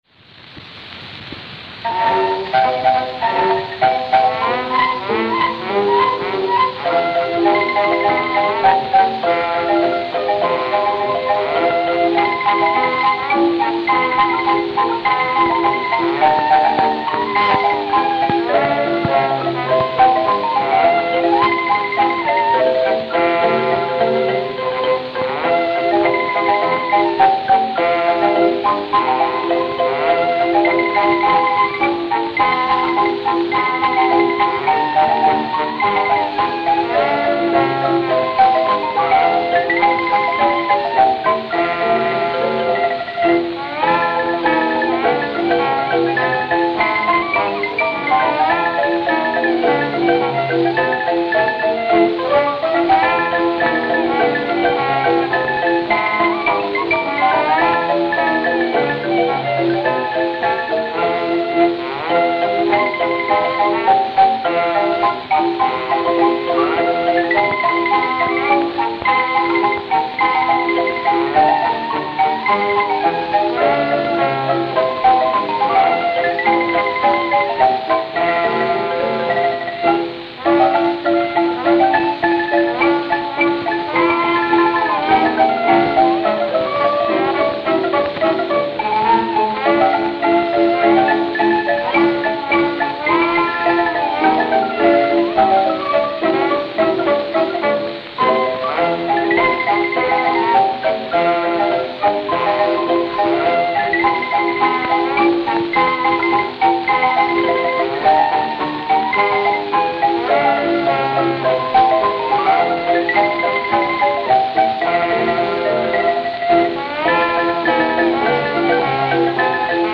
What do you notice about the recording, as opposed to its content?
I even only use the left channel when transferring these.